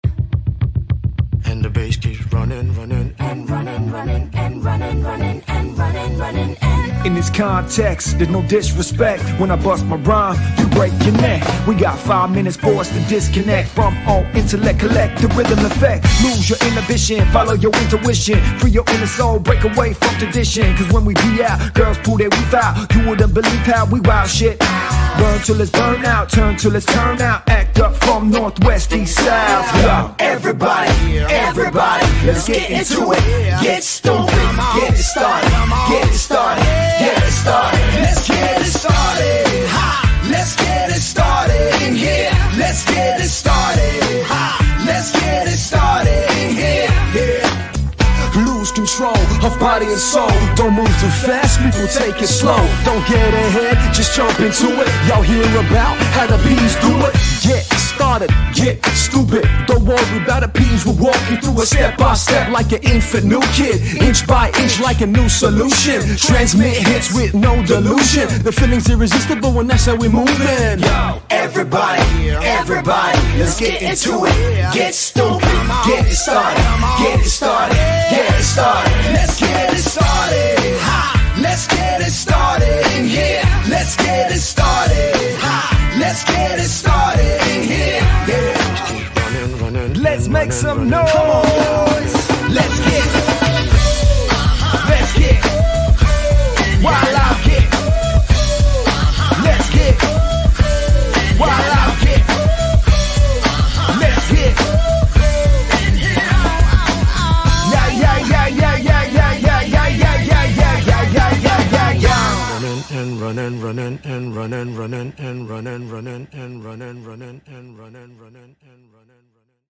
DJ | MC | Rapper
Open format, can play any genres and style.